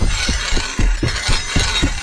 charge_attack_loop.wav